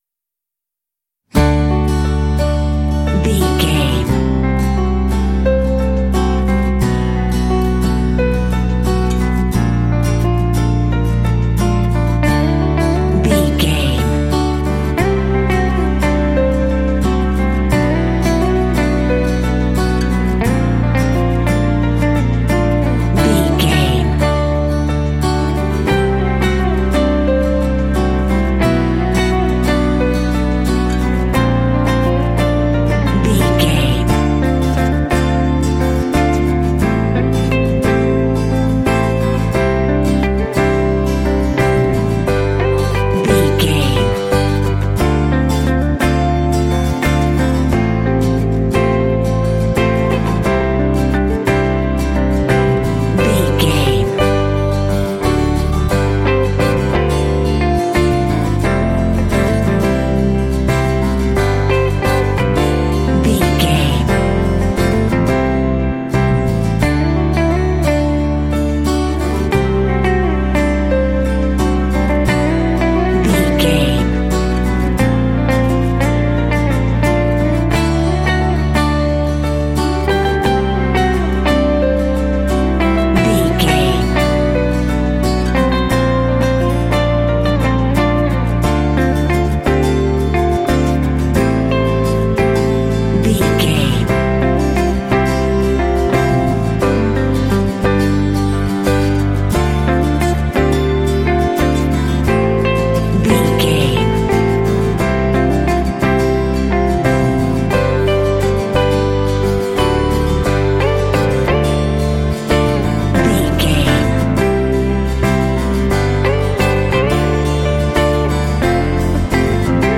Ionian/Major
light
dreamy
sweet
orchestra
horns
strings
percussion
cello
acoustic guitar
cinematic
pop